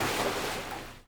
SPLASH_Subtle_06_mono.wav